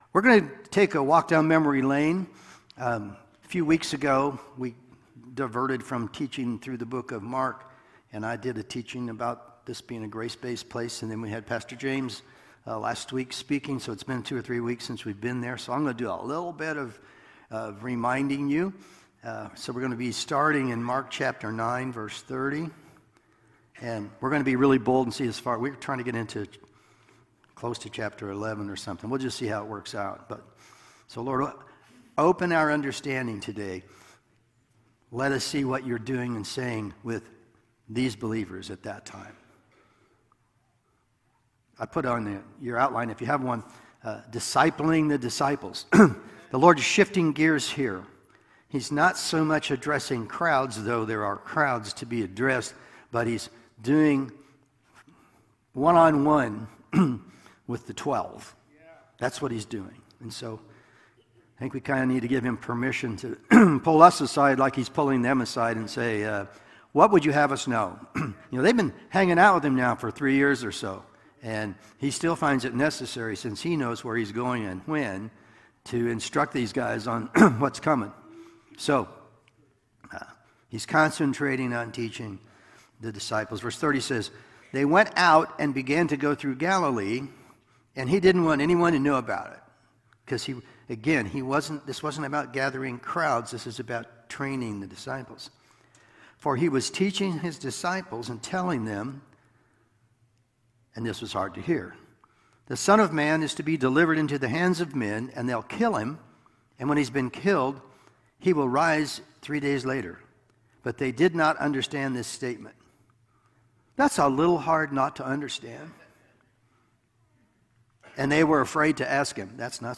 Service Type: Sunday Morning Sermon